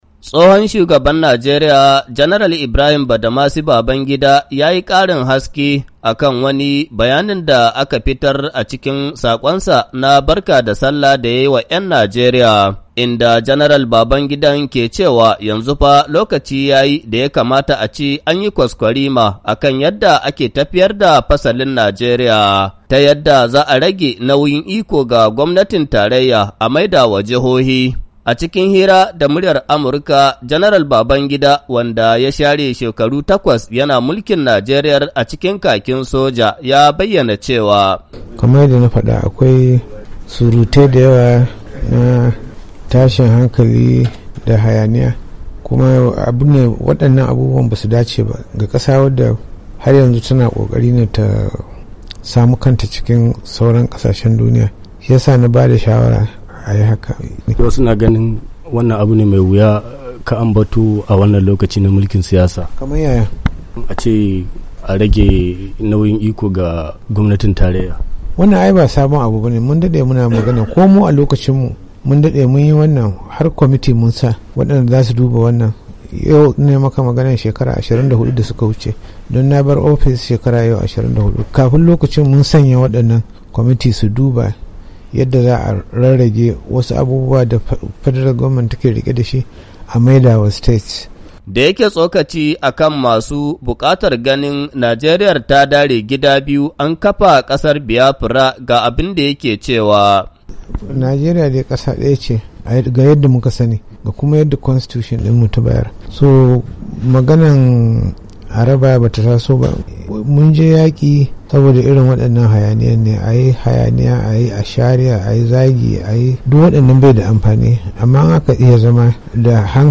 A hirar da ya yi da Muryar Amurka tsohon shugaban Najeriya Janar Ibrahim Babangida ya ce lokaci ya yi da za'a ragewa gwamnatin tarayya iko a ba jihohi saboda ko a lokacinsa sun kafa kwamitin da zai duba sake tsarin mulkin kasar.